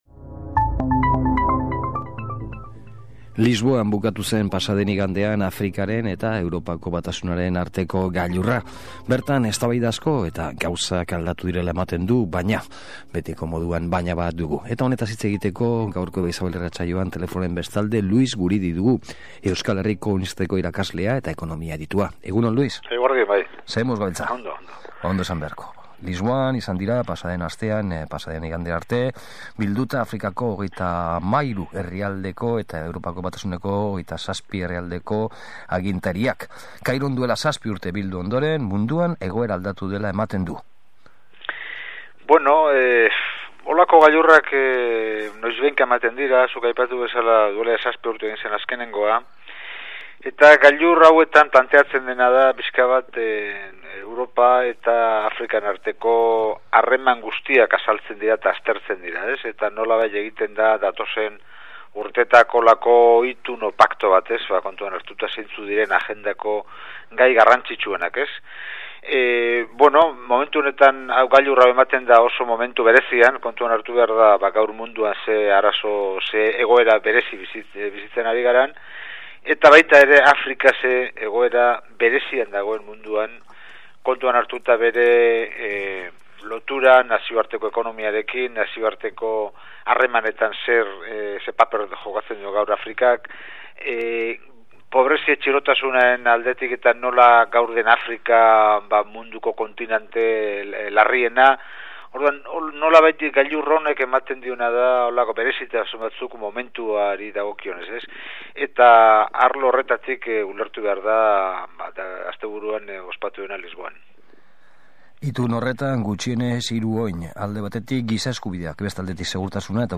Solasaldi hau deskargatu nahi baduzu, egin klik HEMEN . https